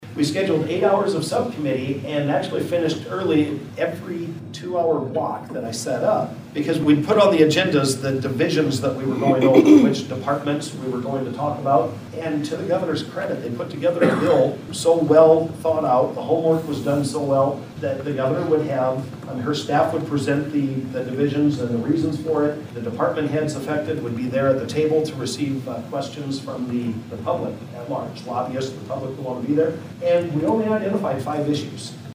District 6 Senator Jason Schultz of Schleswig is leading that legislation in the upper chamber and discussed it briefly during last week’s Carroll Chamber of Commerce Legislative Forum. Schultz says SSB 1123, more commonly referred to as the Government Alignment Bill, makes more sense than a 1,600-page bill should.